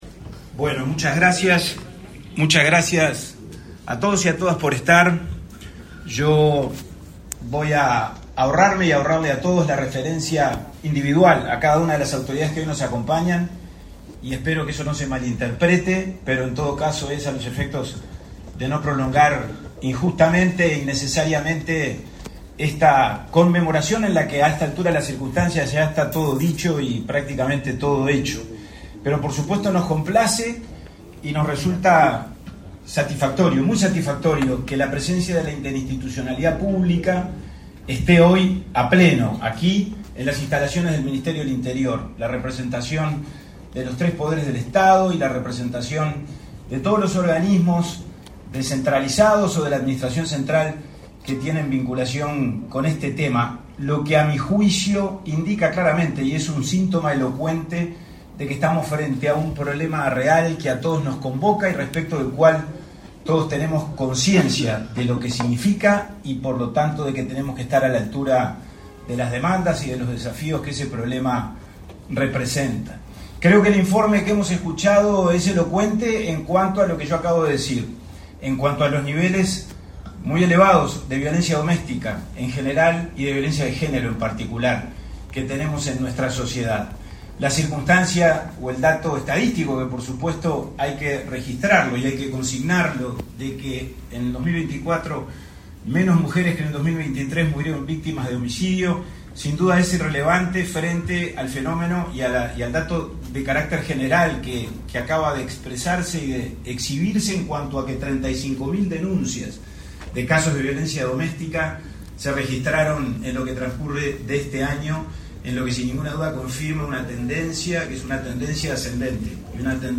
Palabras del ministro interino del Interior, Pablo Abdala
Palabras del ministro interino del Interior, Pablo Abdala 29/11/2024 Compartir Facebook X Copiar enlace WhatsApp LinkedIn En el marco del Día Internacional de la Eliminación de la Violencia contra la Mujer, este 29 de noviembre, el Ministerio del Interior realizó el acto de rendición de cuentas sobre esa problemática y el reconocimiento a policías por su desempeño al respecto. En la ocasión, disertó el ministro interino del Interior, Pablo Abdala.
abdala oratoria.mp3